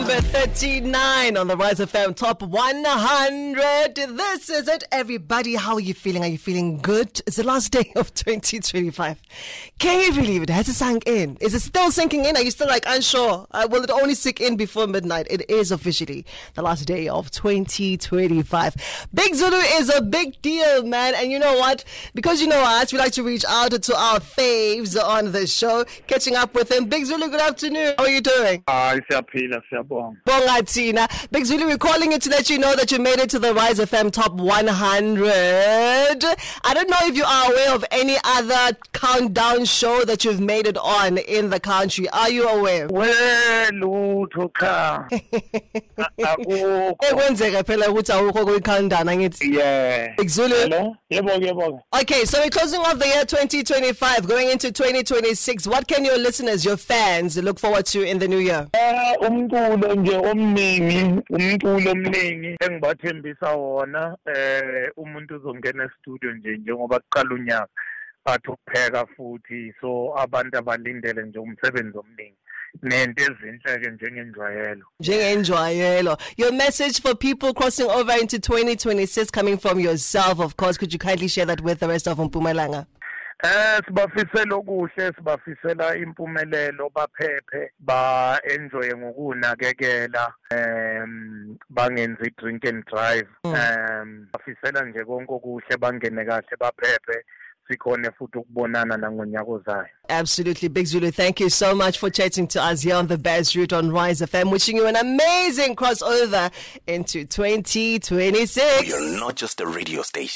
TOP 100 : We speak to BIG ZULU who made it to the Count Down , with his song ABAZAZI ft EMTEE 1:50